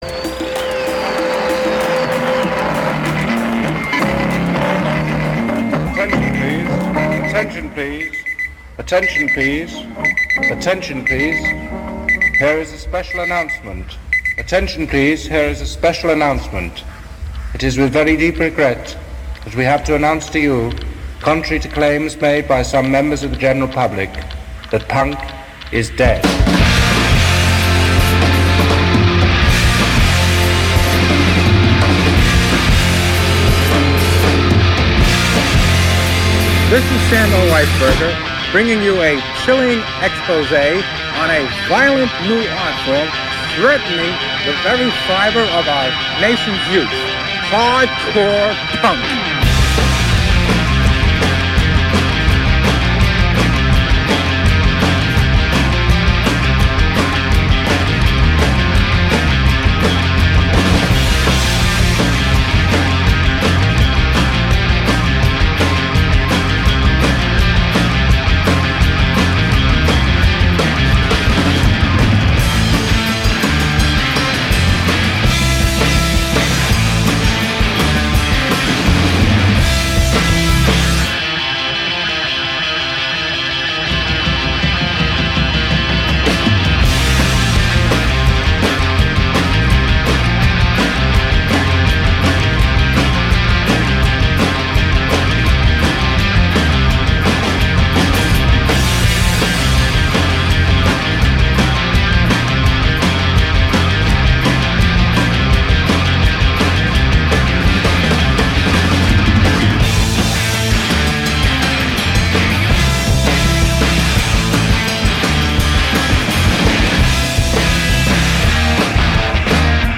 100% chant féminin